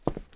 Footstep Concrete Walking 1_04.wav